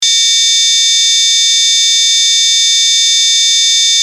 SIRENA 8 TONOS
Sirena con 8 tonos diferentes
Tono_3